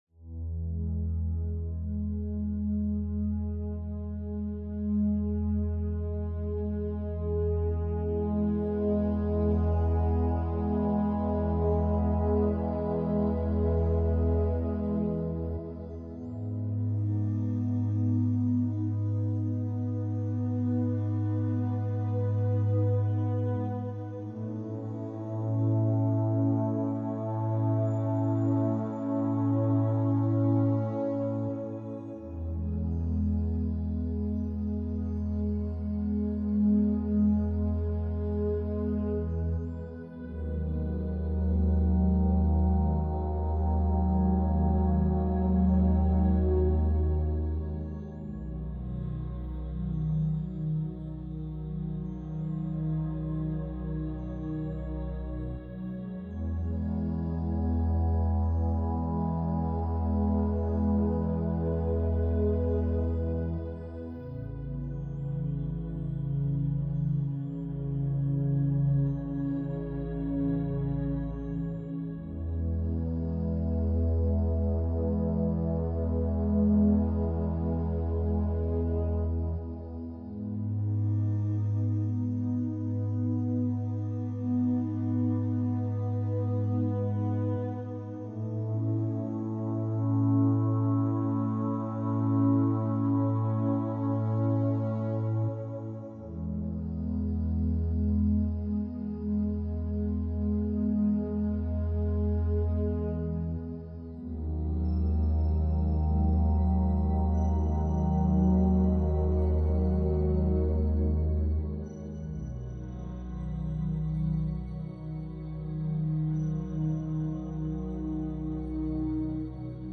2hz- Pure Delta Binaural Beats - Deep Sleep Relaxation Rest